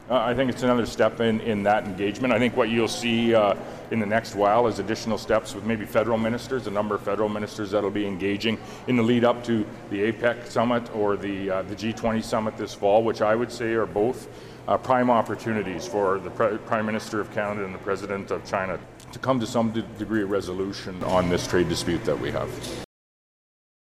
Moe spoke to reporters at the Saskatoon airport prior to departing on a flight to the nation’s capital for additional meetings with Prime Minister Mark Carney and senior cabinet ministers.